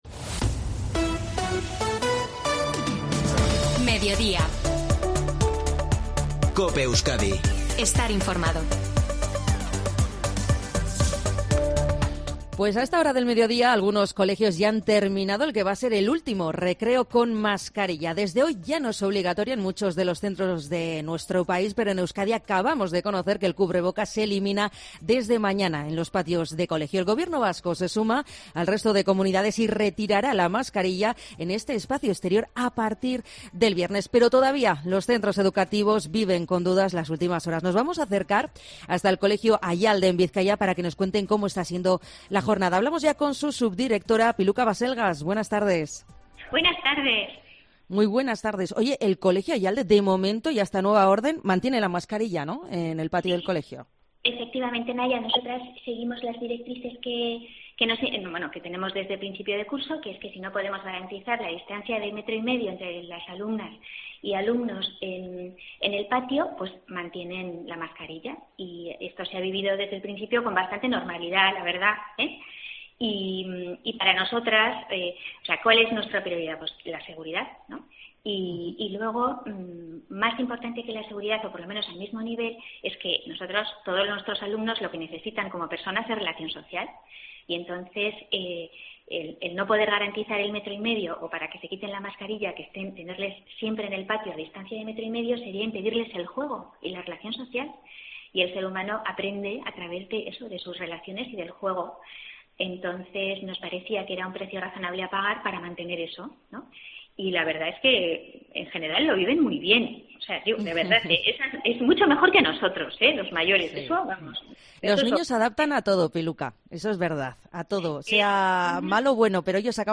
En una entrevista, en COPE Euskadi